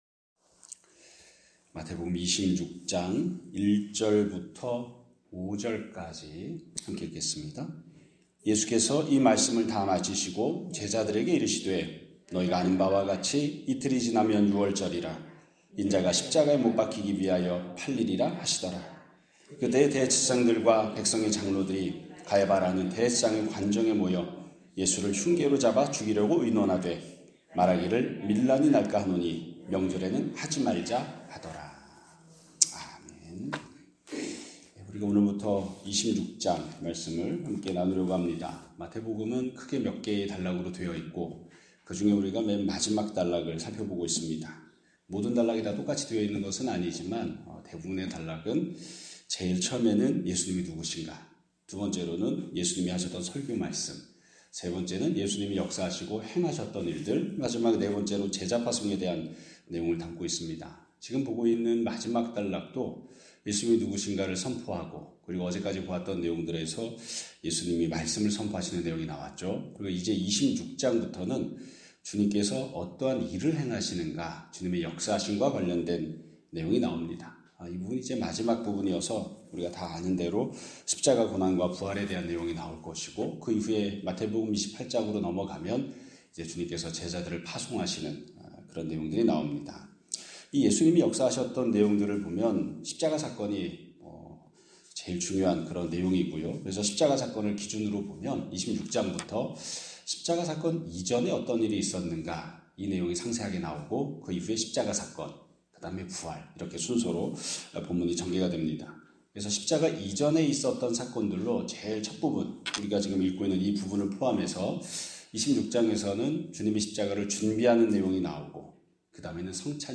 2026년 3월 26일 (목요일) <아침예배> 설교입니다.